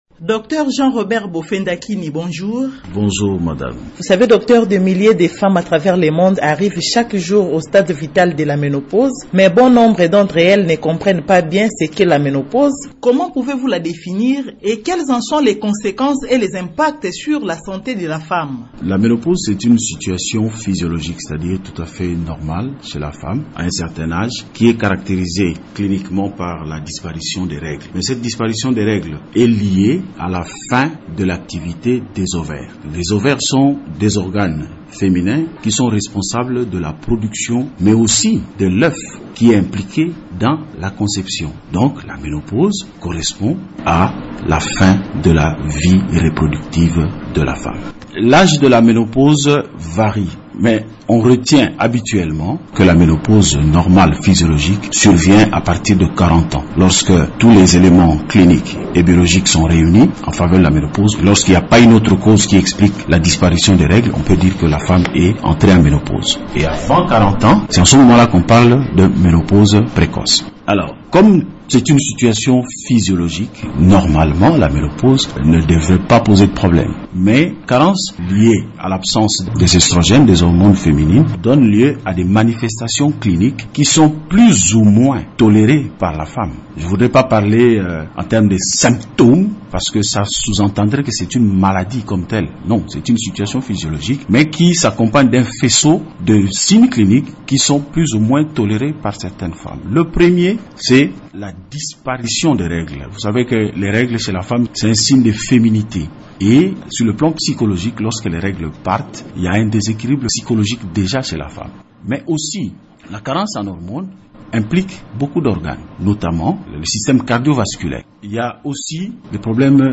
Invité de Radio Okapi, ce gynécologue œuvrant dans la ville de Matadi au Kongo-Central, a rappelé que la ménopause est une situation physiologique normale qui se manifeste chez la femme par la cessation des règles ou menstruations après 40 ans.